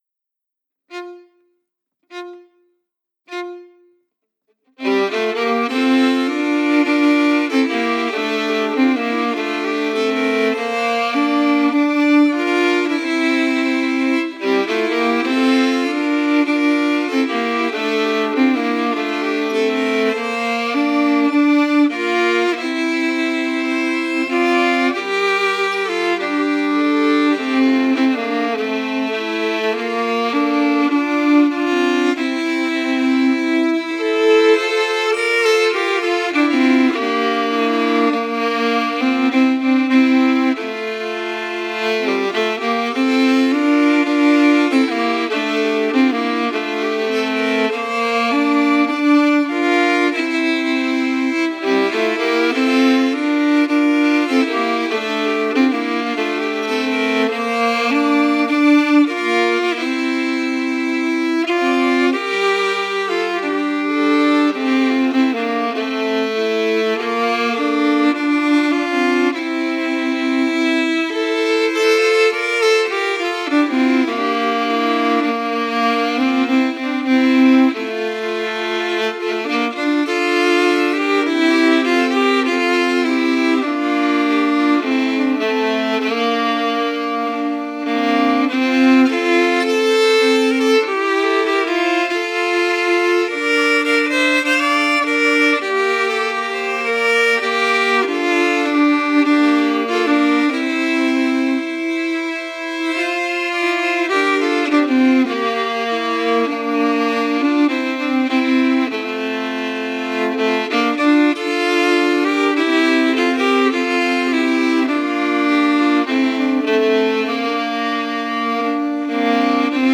Key: F*
Form: Slow March
Harmony emphasis
Genre/Style: Swedish slow march